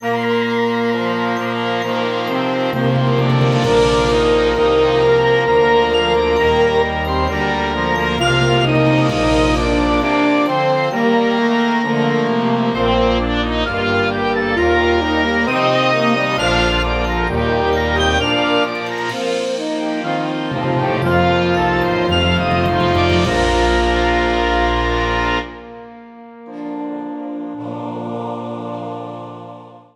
für Jodelgesang und Blasorchester (Harmonie)
Beschreibung:Blasmusik; Volksmusik; Jodel; Jodellied